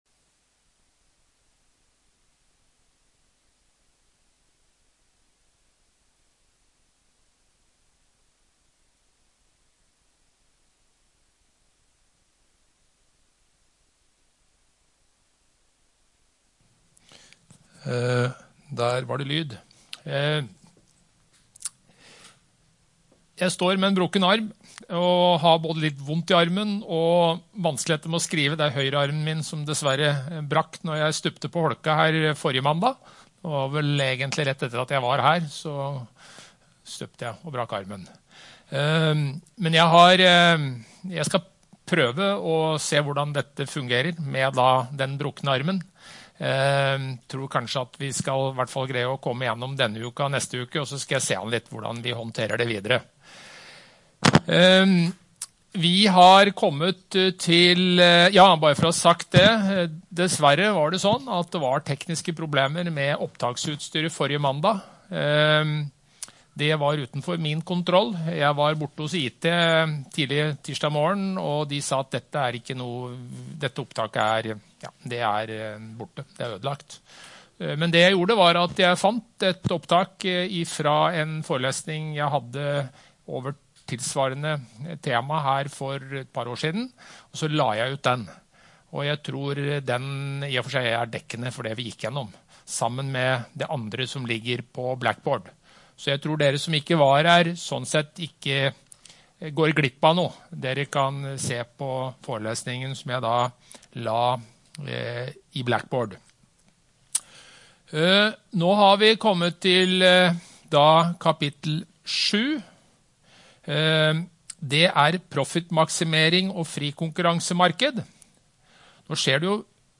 Mikroøkonomi - NTNU Forelesninger på nett